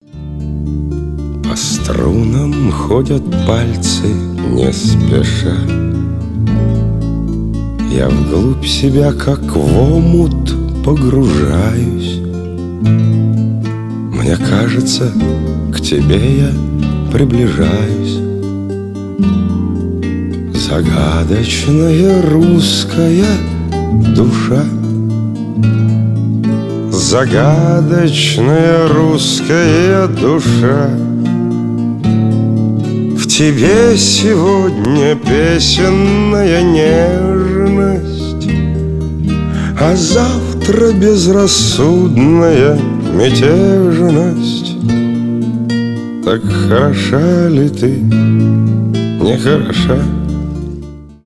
акустика
душевные
гитара